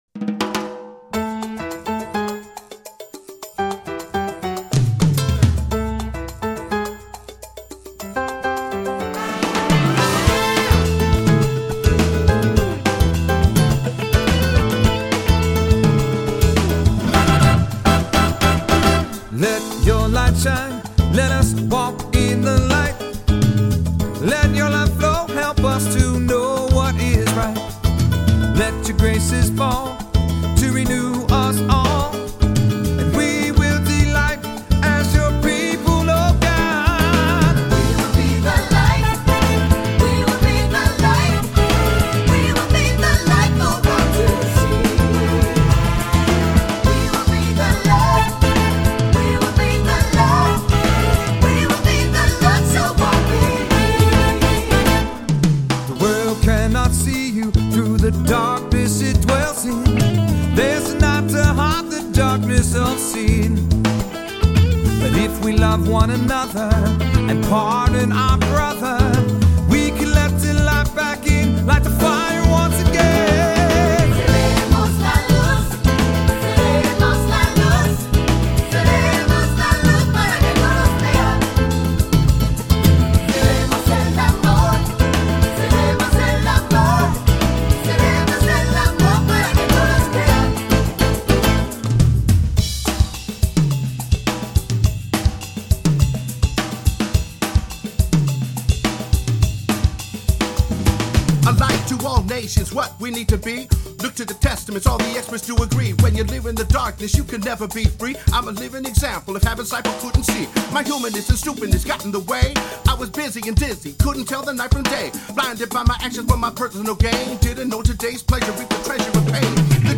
Voicing: Three-part equal; Solo; Assembly